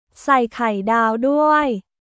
サイ・カイダオ・ドゥアイ